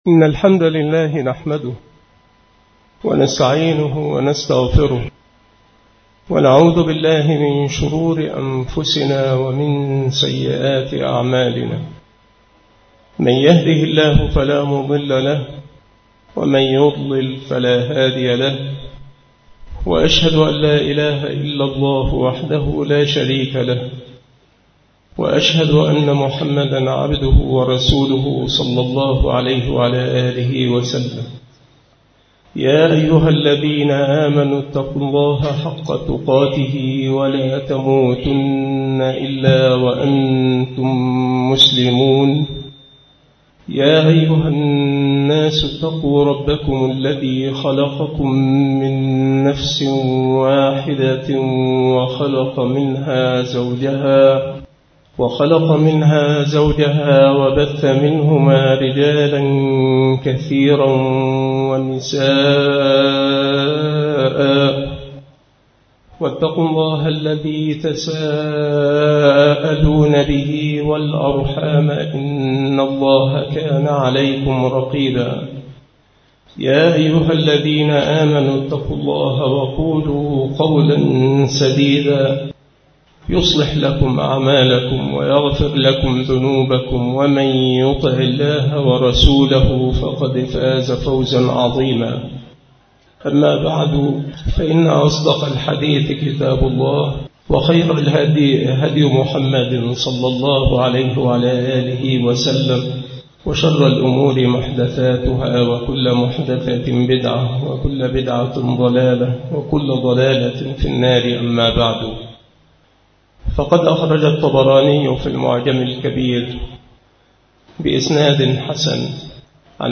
التصنيف شهر رمضان
مكان إلقاء هذه المحاضرة بالمسجد الشرقي - سبك الأحد - أشمون - محافظة المنوفية - مصر